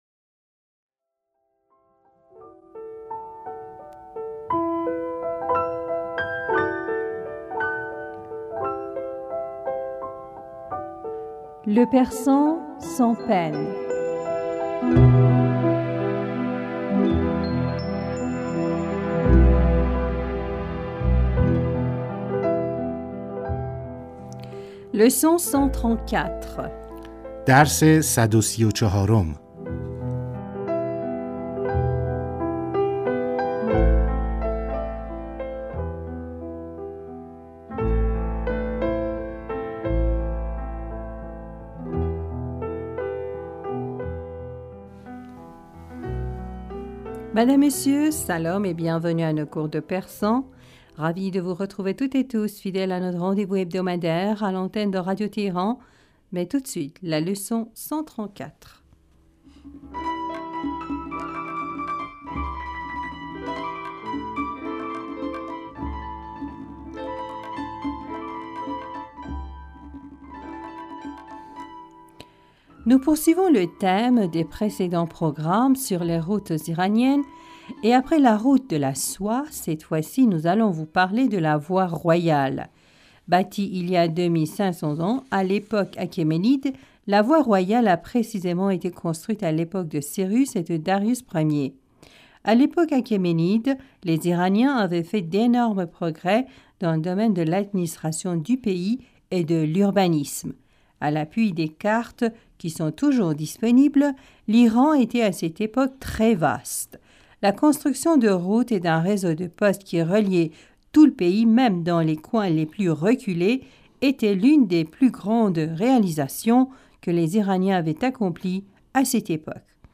Madame, Monsieur, Salam et bienvenus à nos cours de persan.
Ravis de vous retrouver, toutes et tous fidèles à notre rendez-vous hebdomadaire à l'antenne de Radio Téhéran.